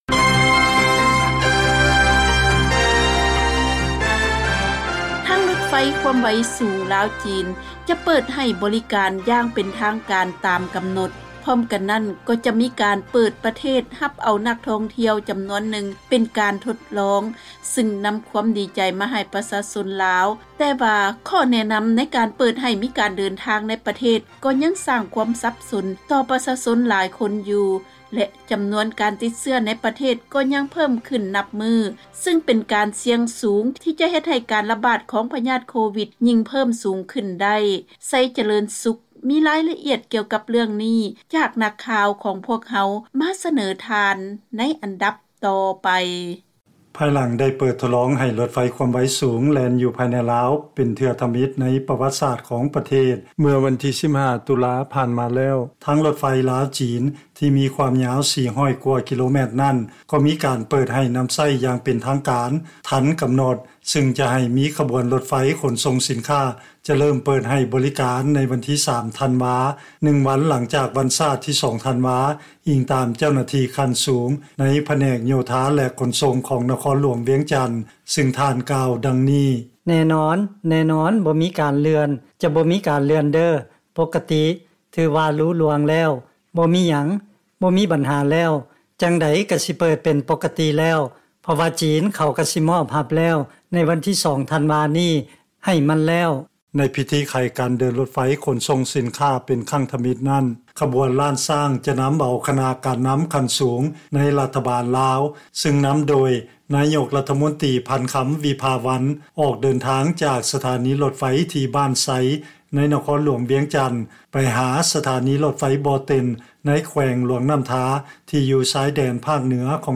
ເຊີນຟັງລາຍງານ ພິທີໄຂການເດີນລົດໄຟຄວາມໄວສູງ ລາວ-ຈີນ ຢ່າງເປັນທາງການ ໃນວັນທີ 3 ທັນວາ ຕາມແຜນການທີ່ໄດ້ວາງໄວ້